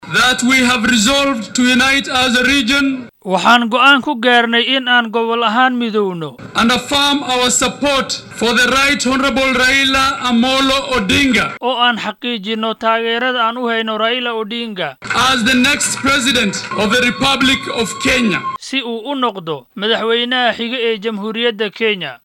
Barasaabkii hore ee Wajeer Axmad Cabdullahi oo akhriyay qaraarka ka soo baxay go’aanka ay qaateen madaxda ayaa yiri